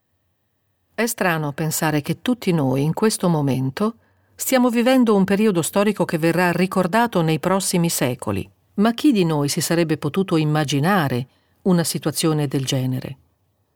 It is possible to paste-in some noise-floor from elsewhere in the recording, into where there is not enough …